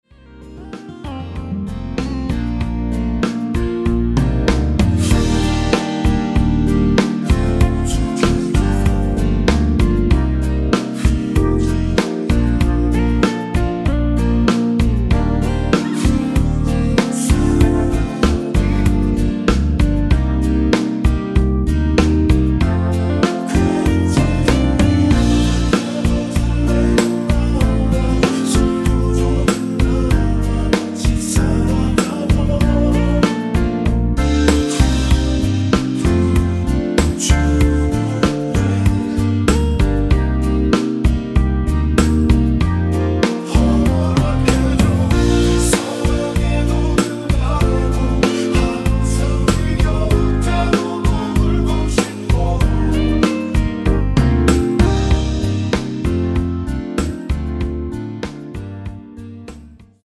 코러스 포함된 MR 입니다.(미리듣기 참조)
Bb
앞부분30초, 뒷부분30초씩 편집해서 올려 드리고 있습니다.
중간에 음이 끈어지고 다시 나오는 이유는
곡명 옆 (-1)은 반음 내림, (+1)은 반음 올림 입니다.